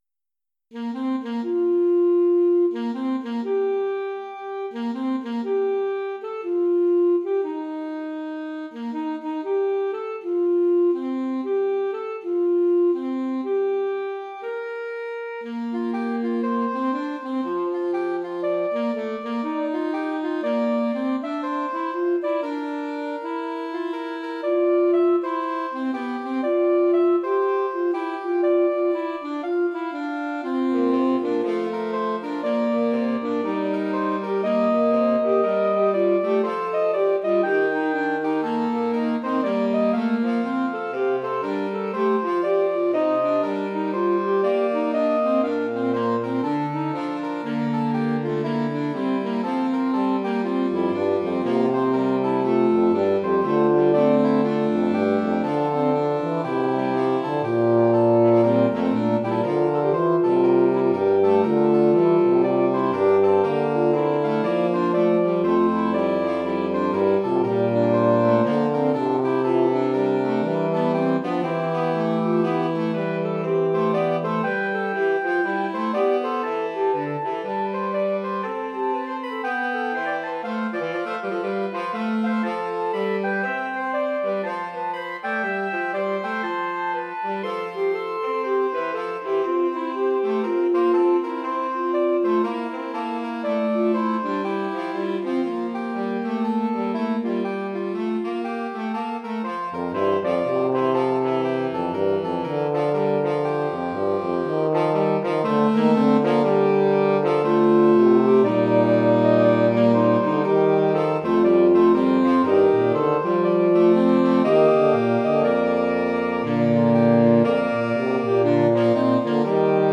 Here are a few (headphones are recommended, I’m no sound engineer and it sounds like shit on laptop speakers):
On a tune I made up as a child, for saxophones.
fugue11sax.mp3